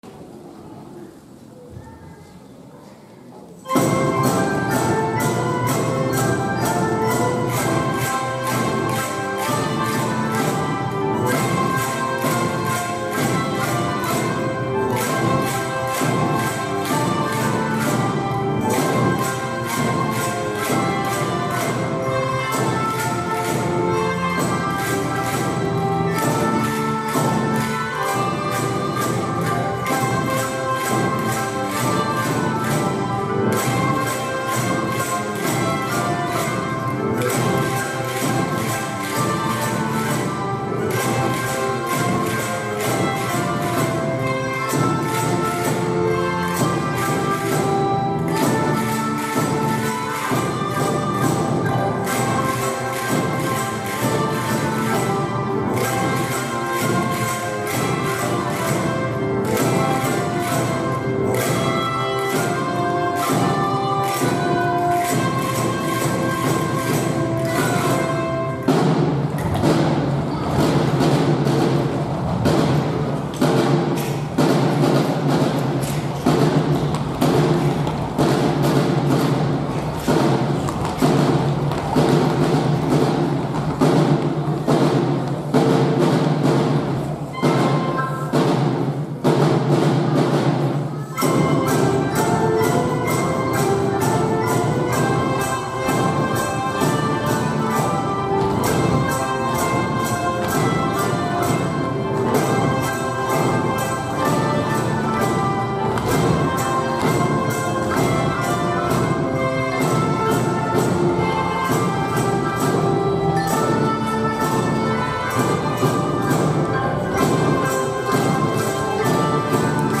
１・２・３年の合奏（リズム表現）。
１年生はカスタネットとリズム表現。２年生は鍵盤ハーモニカ。３年生はいろんな楽器で演奏しました。
１年生は前半、カスタネットで軽快なリズムをつくり、後半はダンスでからだ全部を使って表現しました。
２年生は難しい指使いにもチャレンジし、鍵盤ハーモニカでメロディーを演奏しました。